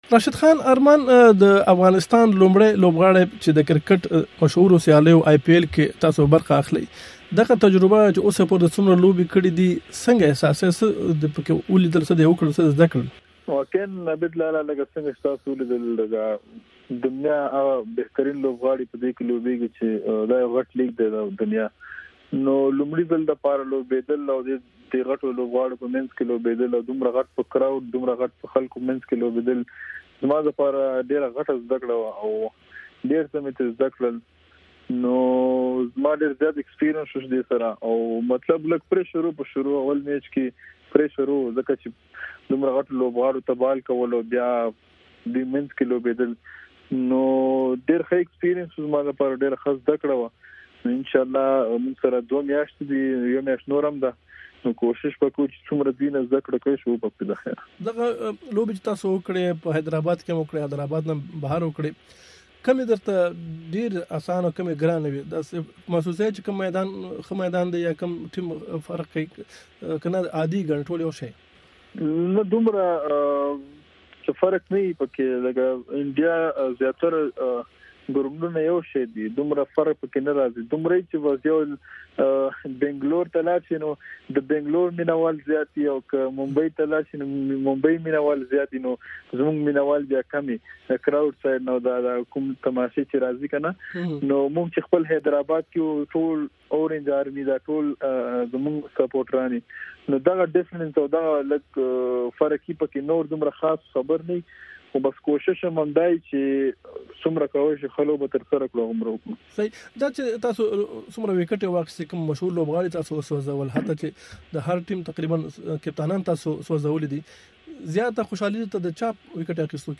د افغانستان د کرکټ ځوان ستوري راشد خان سره مرکه